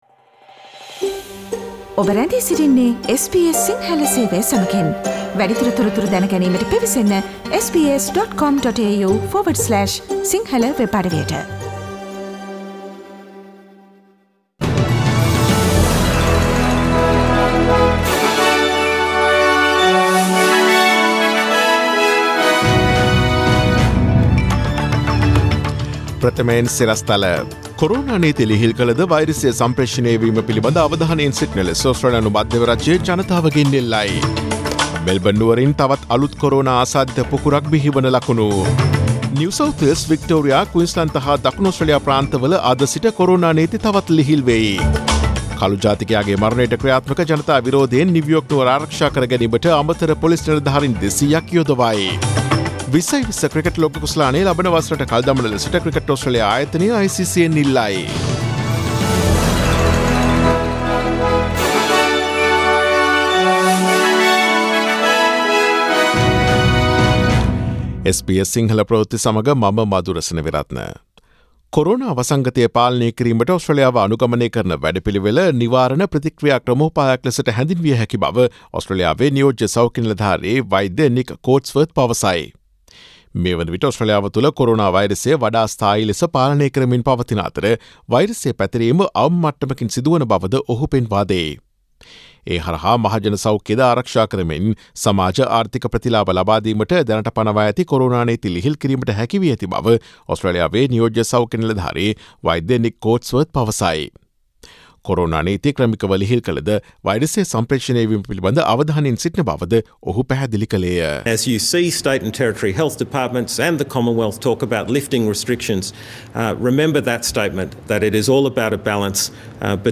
Daily News bulletin of SBS Sinhala Service: Monday 01 June 2020